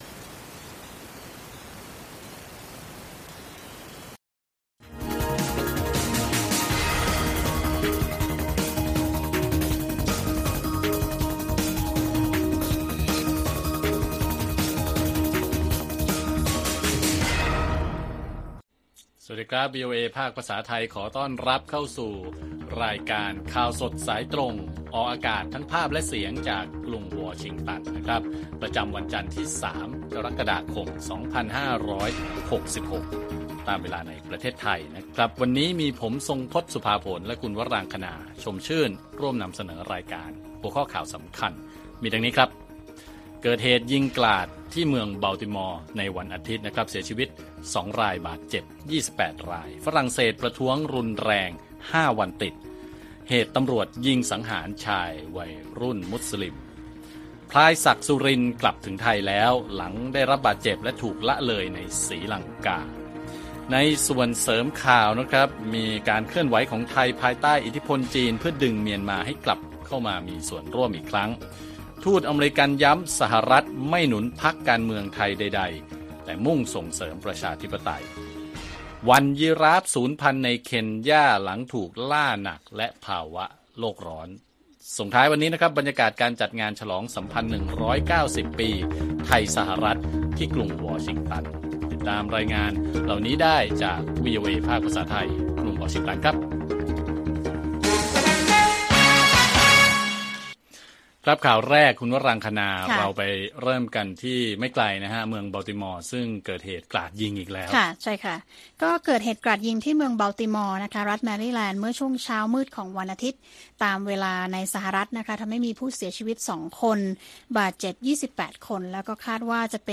ข่าวสดสายตรงจากวีโอเอไทย 6:30 – 7:00 น. วันที่ 3 ก.ค. 2566